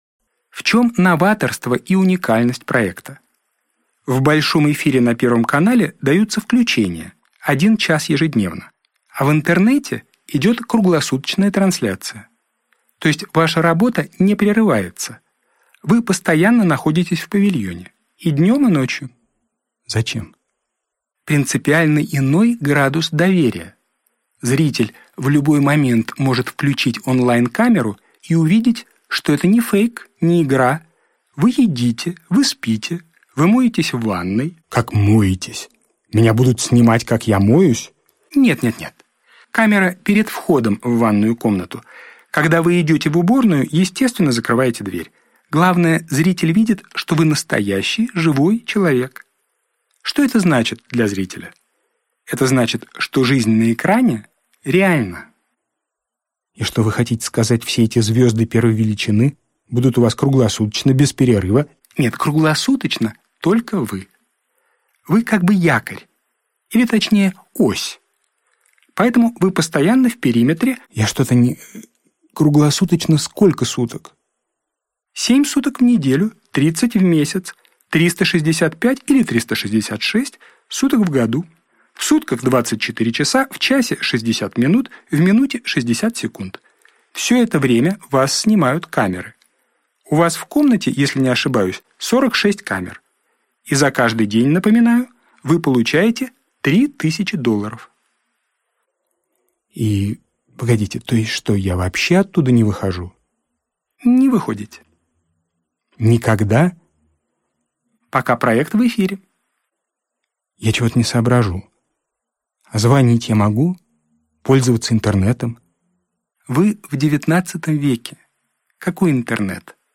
Аудиокнига Тебя все ждут | Библиотека аудиокниг